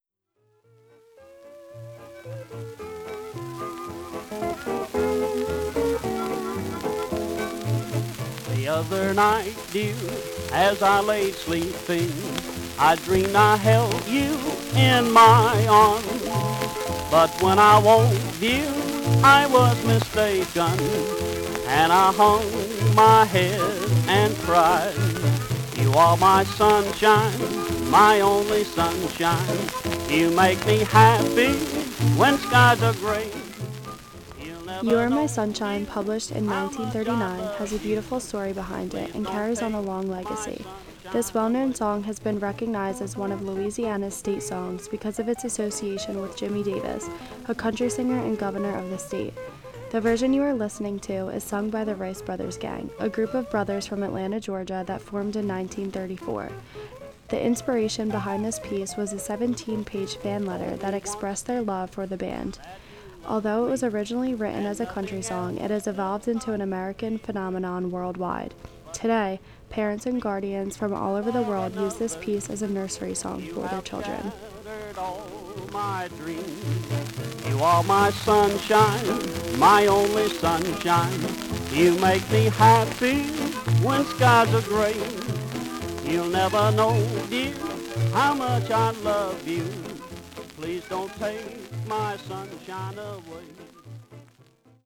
a group of brothers from Atlanta, Georgia